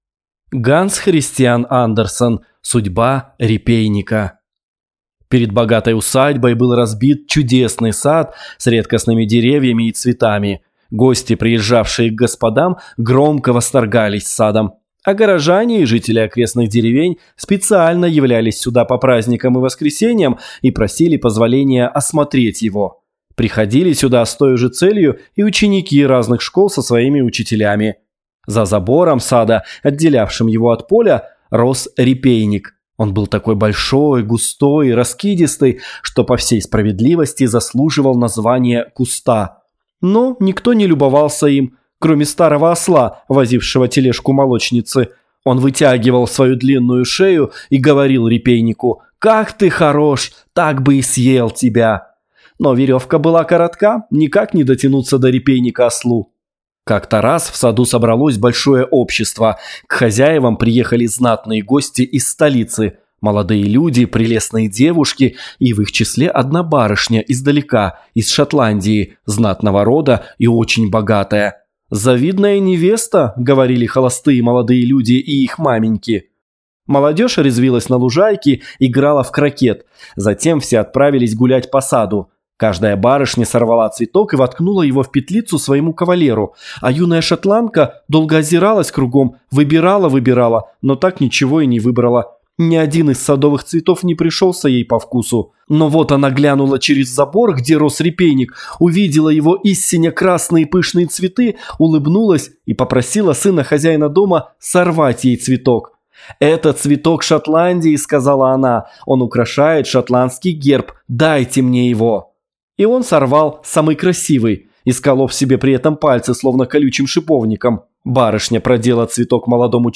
Аудиокнига Судьба репейника | Библиотека аудиокниг